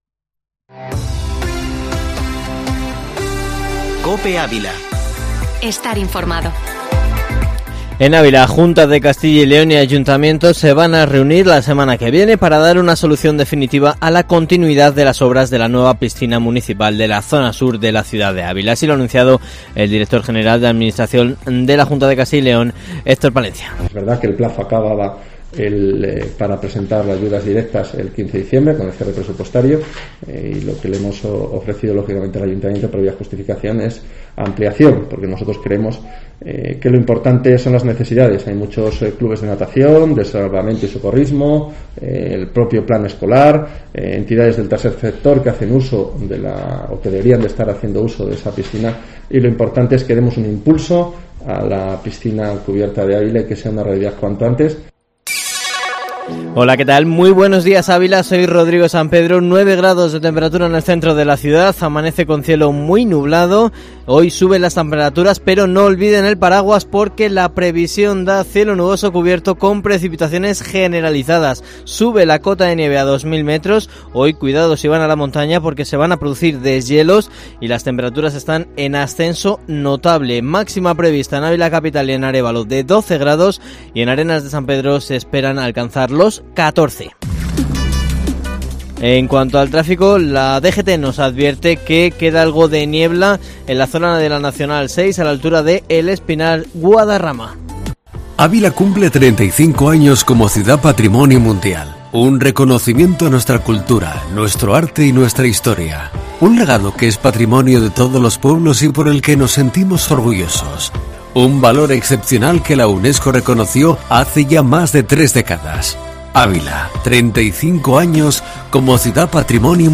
Informativo matinal Herrera en COPE Ávila 10/12/2020